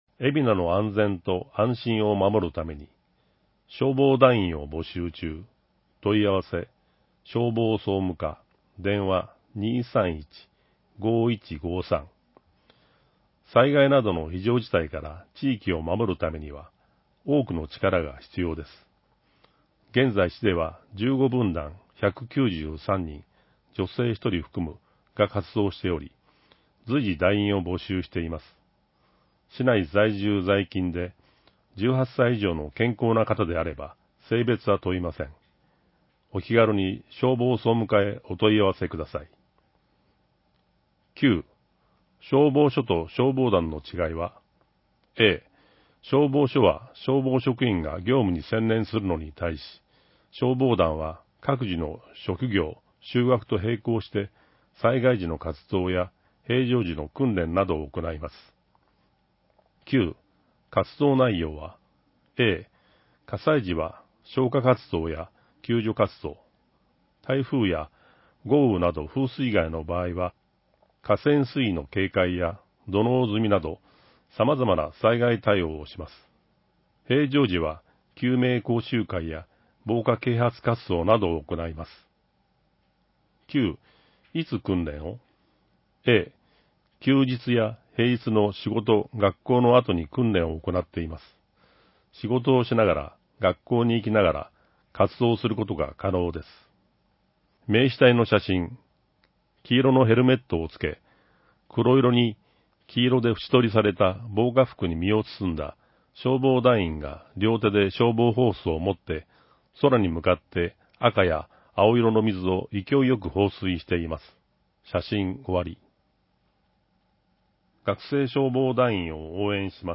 広報えびな 平成29年8月1日号（電子ブック） （外部リンク） PDF・音声版 ※音声版は、音声訳ボランティア「矢ぐるまの会」の協力により、同会が視覚障がい者の方のために作成したものを登載しています。